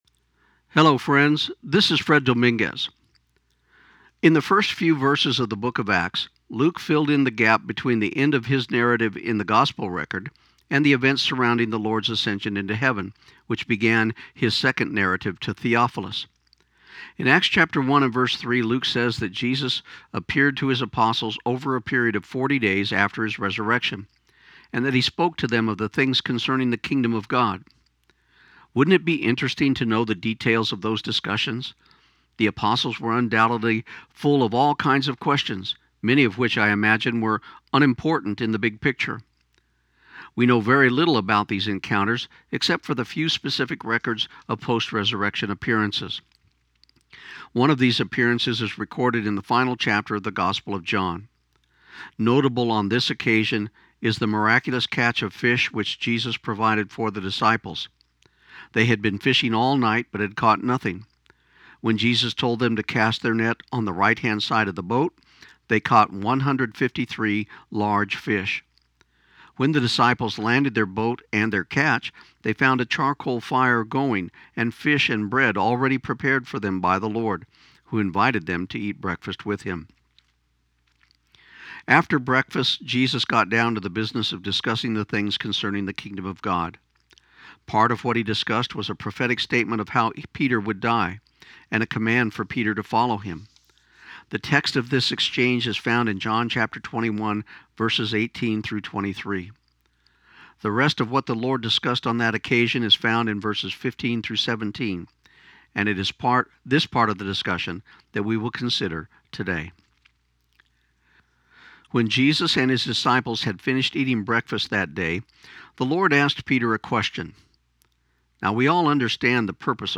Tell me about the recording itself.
This program aired on KIUN 1400 AM in Pecos, TX on December 4, 2015.